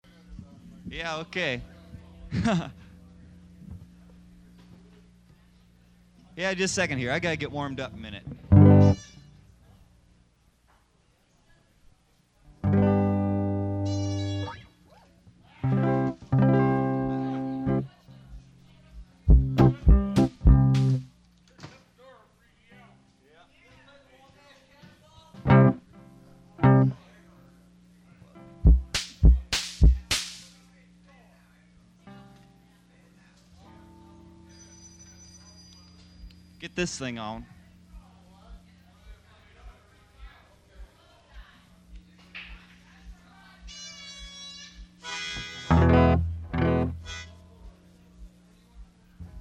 Live at Joe's Joint Vol. 1